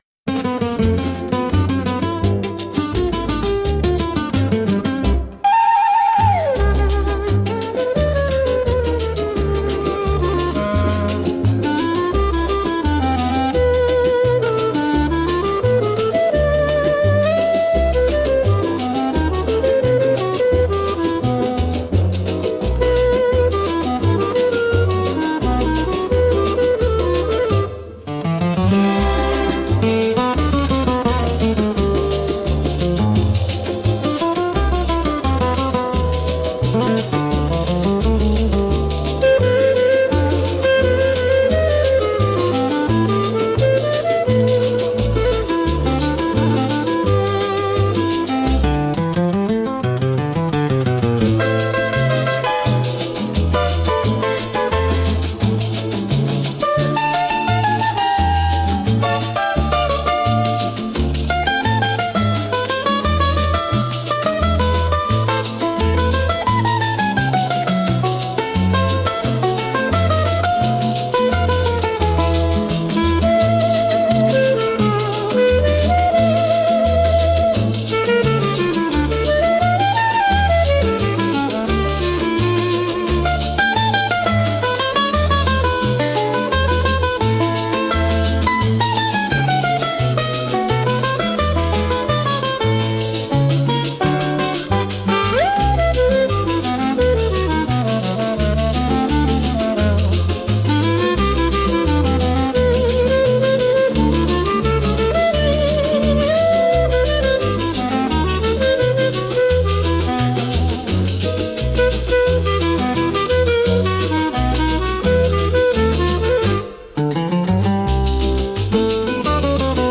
instrumental 7